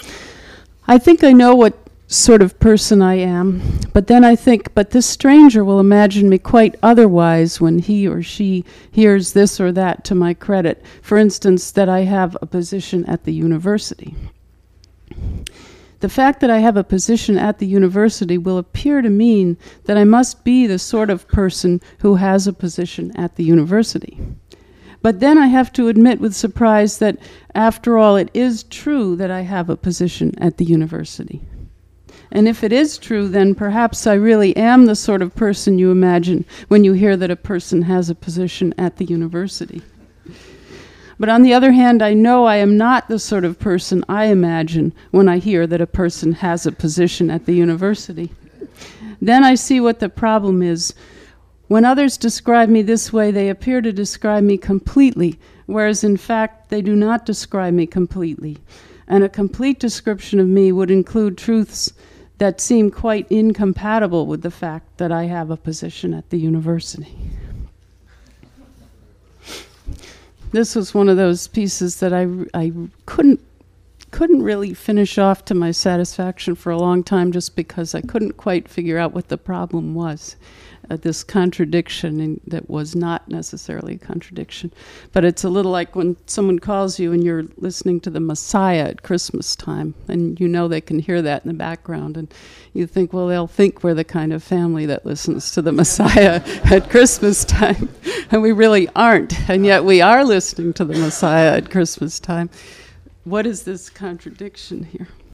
Nghe Lydia Davis đọc bài thơ này ở đây: